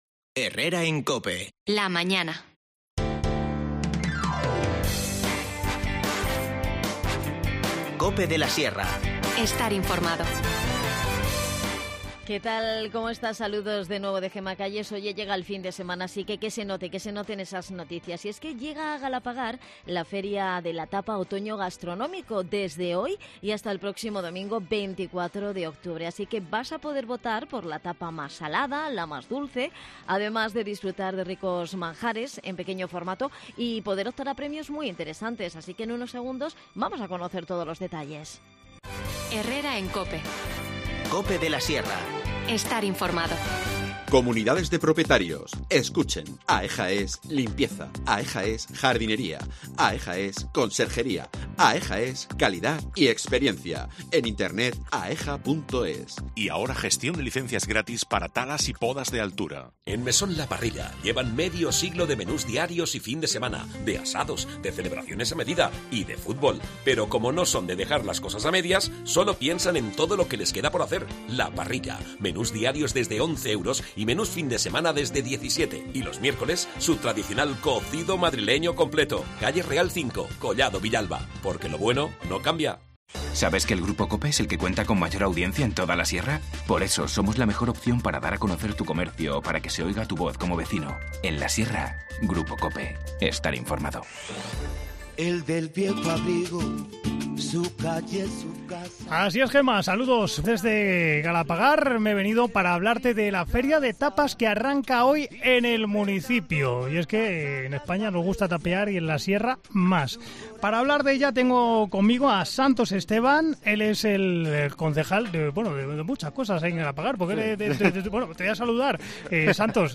Hablamos con Santos Esteban, concejal de Comercio.